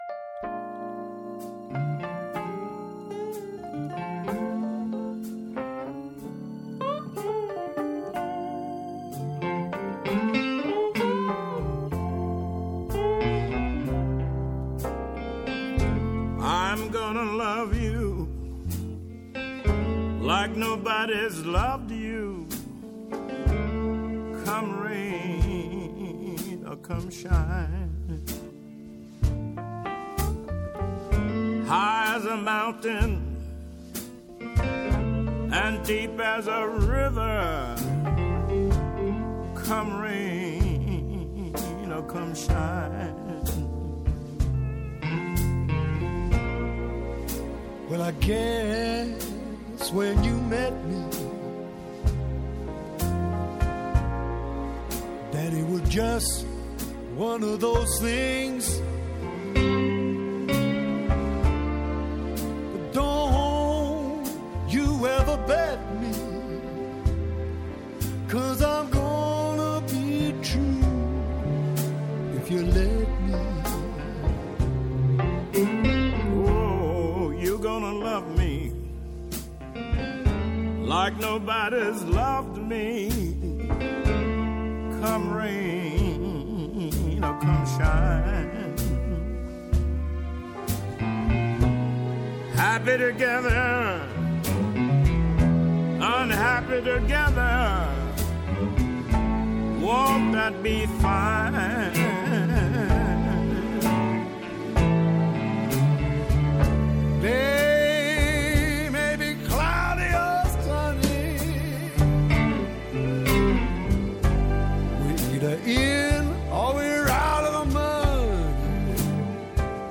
Σάββατο και Κυριακή στο Kosmos 93.6
ΜΟΥΣΙΚΗ